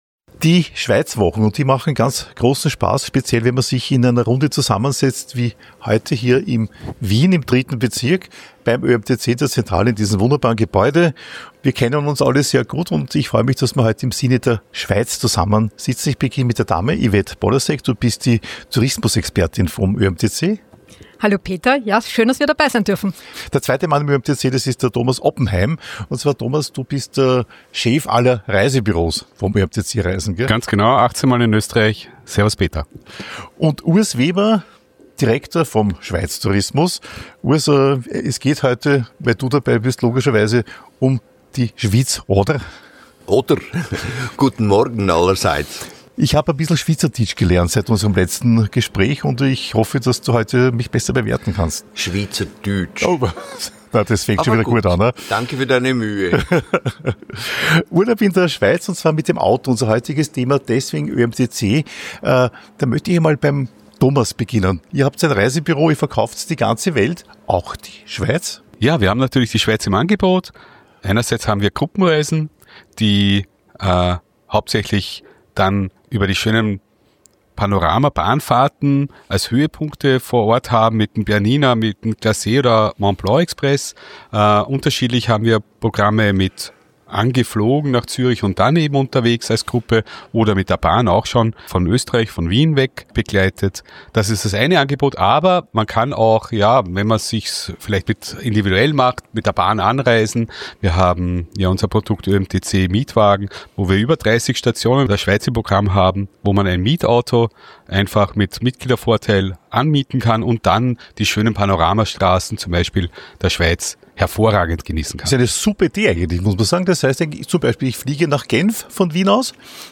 Herrliche Bahnfahrten faszinieren dabei genauso wie wunderschöne Alpenstraßen für Autofahrer und Motorradfahrer. Zu diesem Thema habe ich eine Talk-Runde in der ÖAMTC Zentrale in Wien getroffen.